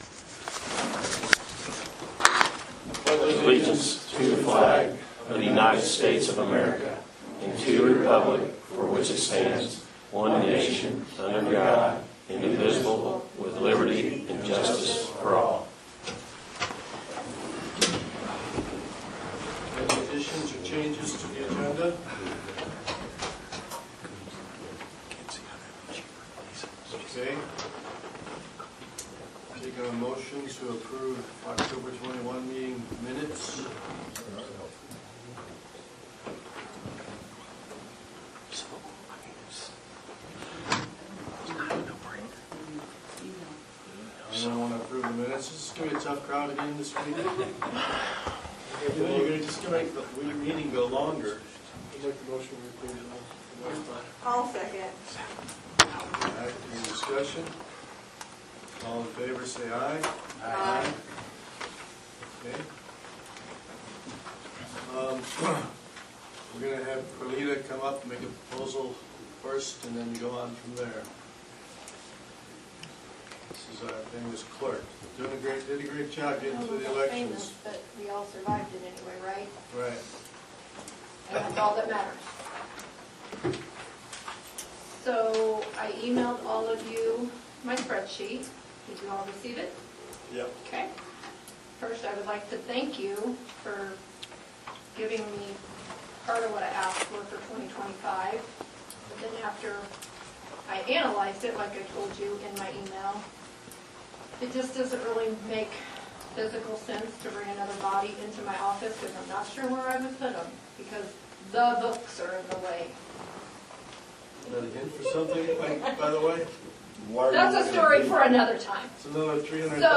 Agenda County Council November 18, 2024